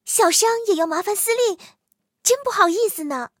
T43小破修理语音.OGG